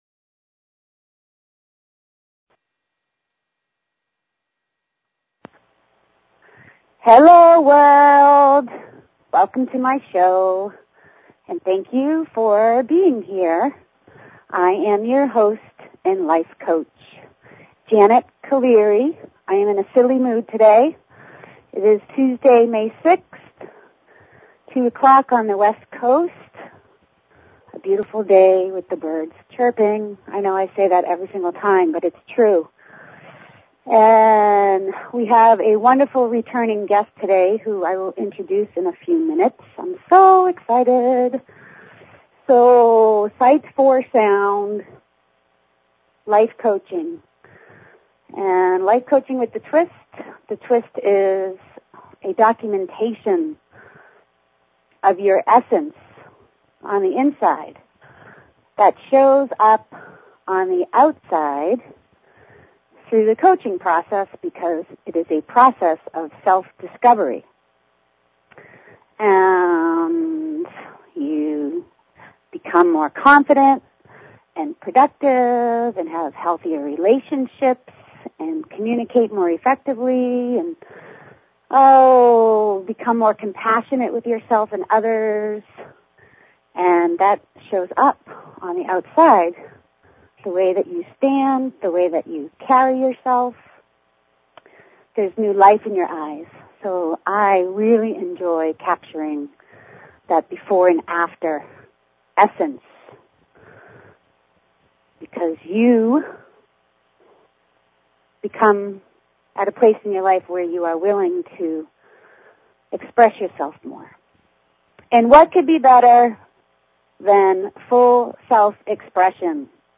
Talk Show Episode, Audio Podcast, Sight_for_Sound and Courtesy of BBS Radio on , show guests , about , categorized as
She encourages you to call in and ask questions or share thoughts!!!